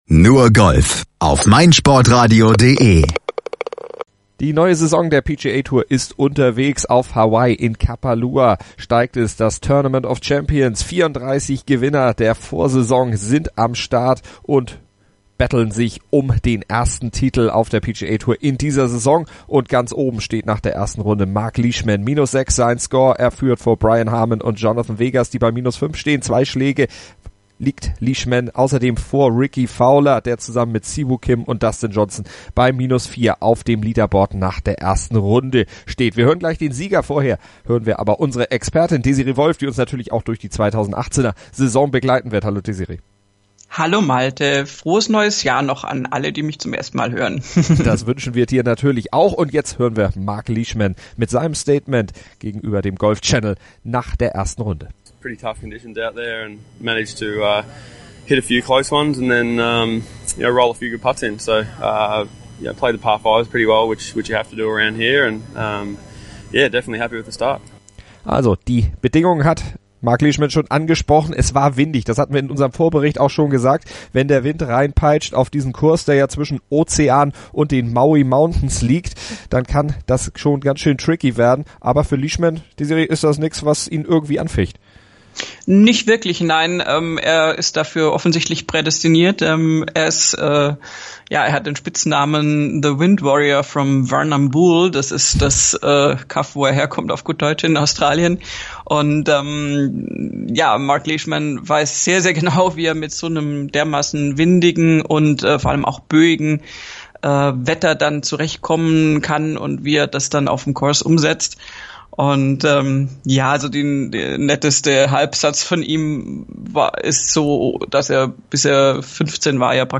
Auch darüber diskutieren unsere Nur Golf-Experten - und sie werfen einen Blick an das untere Ende des Leaderboards, wo sich nicht nur Jordan Spieth nach einer für ihn eher mäßigen Runde tummelt, sondern wo Brooks Koepka ganz am Ende rangiert.